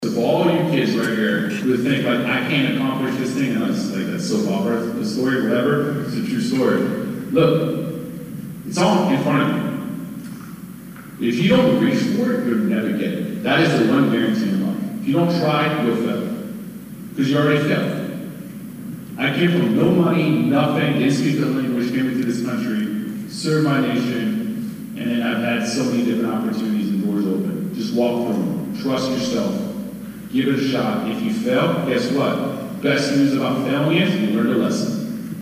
Duxbury High School held a ceremony Wednesday paying tribute to two Medal of Honor winners.
Retired Army Capt. Florent Groberg, was injured while protecting his unit from a suicide attack in Afghanistan, he told the students they should always challenge themselves.